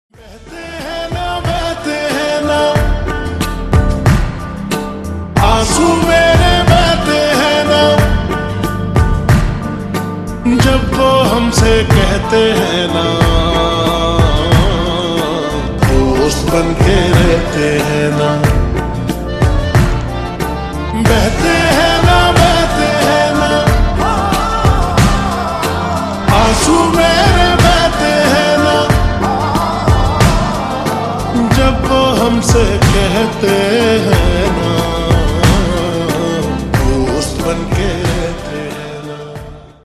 Hindi Songs